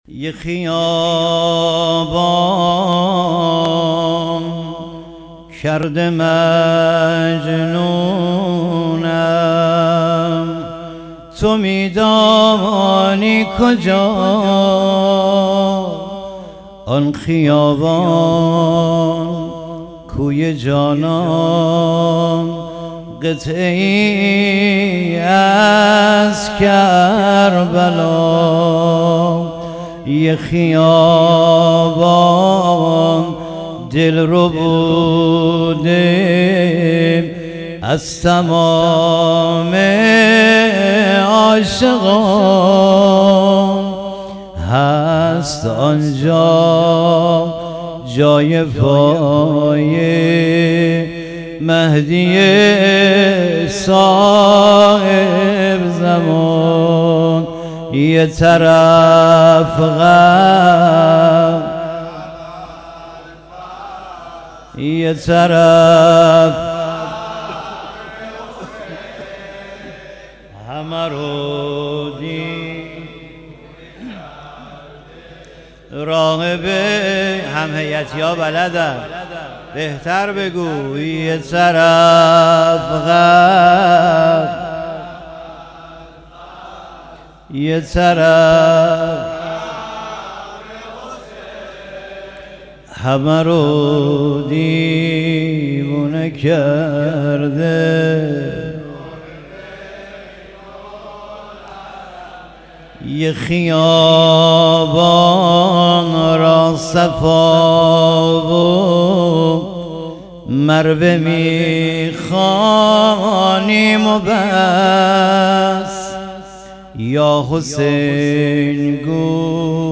زمزمه یه خیابان کرده مجنونم
محرم و صفر 1396